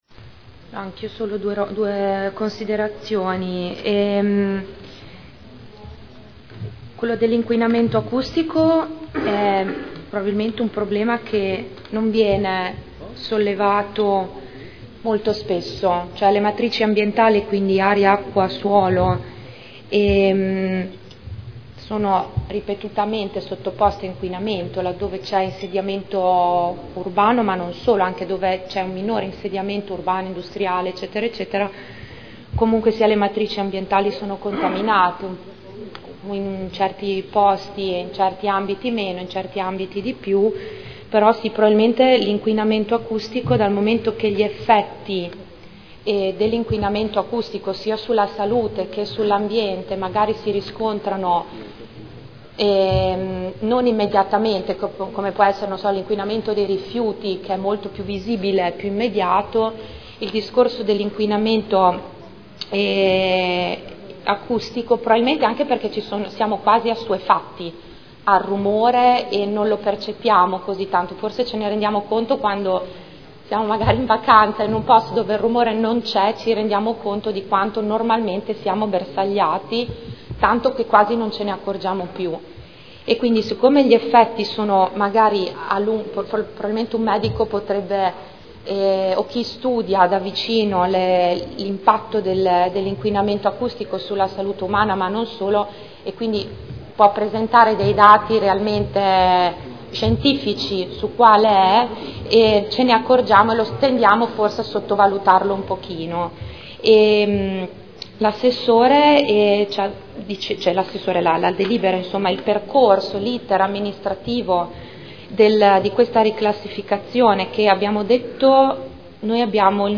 Proposta di deliberazione: Aggiornamento della classificazione acustica del territorio comunale – Adozione. Dibattito
Audio Consiglio Comunale